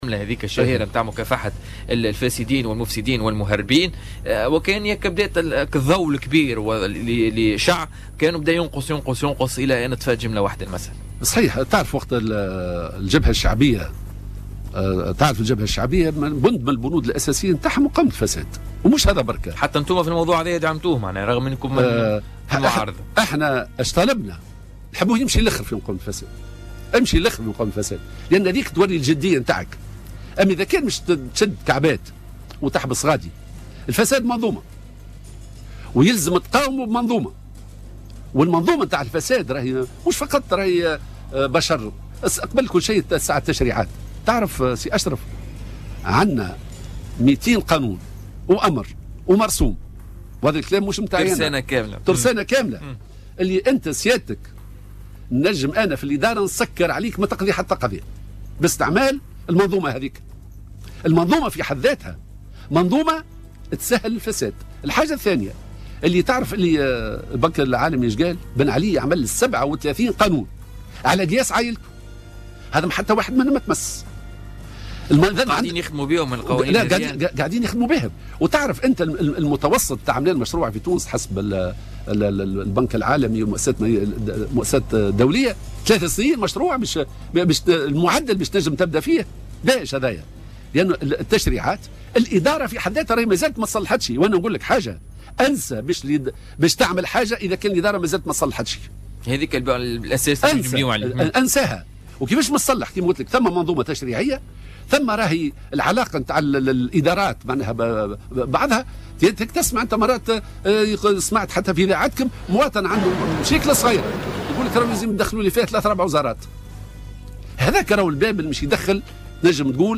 وأضاف ضيف "بوليتيكا" انه تم التقدم باستفسار في البرلمان لرئيس الحكومة لتوضيح الأمر، مشددا على ضرورة مقاومة الفساد بانعدام أي شبهة فساد تحوم حول المكلفين بمقاومته.